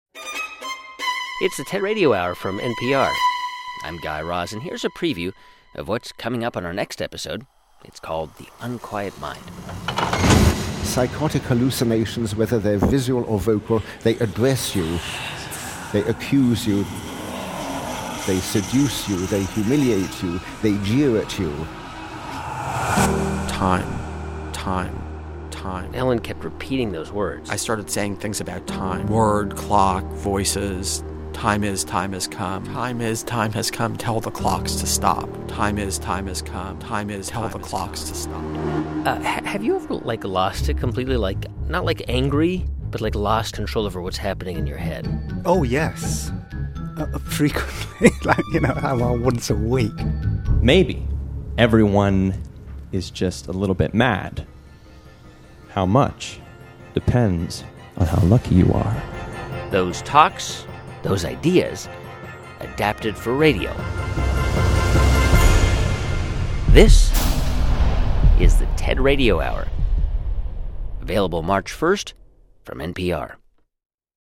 New episodes of the TED Radio Hour, with host Guy Raz, start March 1.